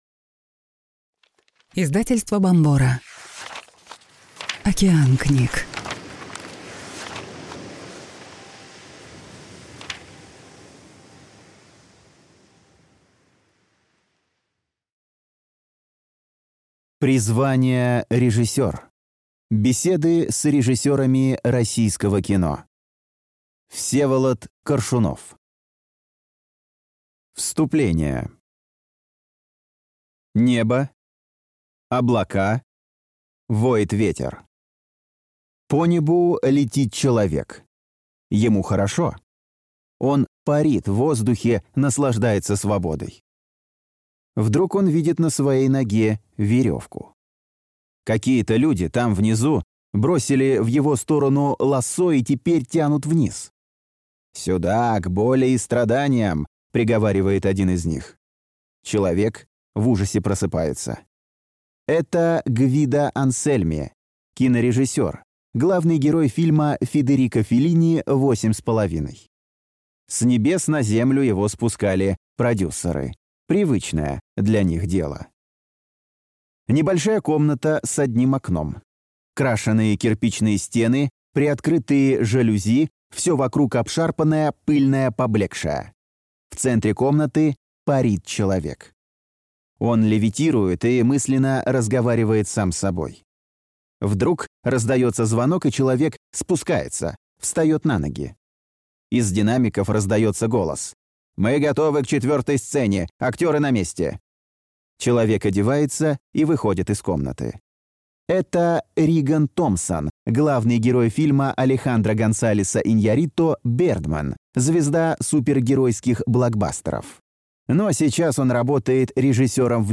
Аудиокнига Призвание режиссёр. Беседы с режиссёрами российского кино | Библиотека аудиокниг